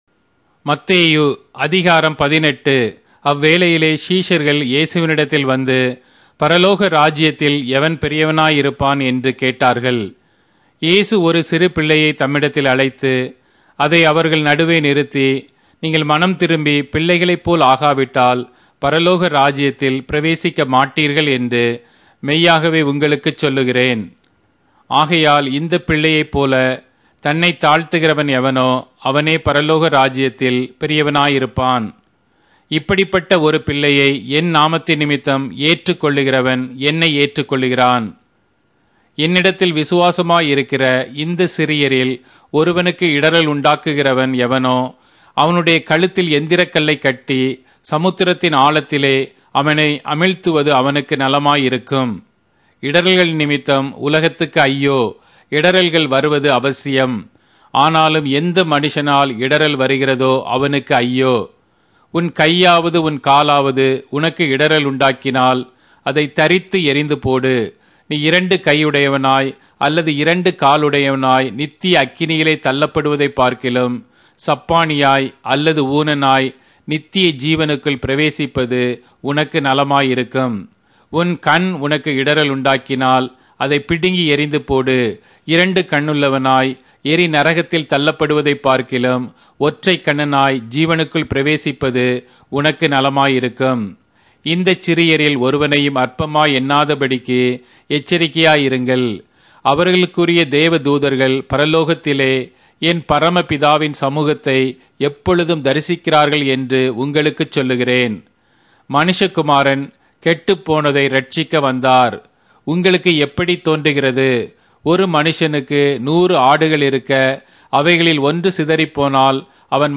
Tamil Audio Bible - Matthew 11 in Ocvhi bible version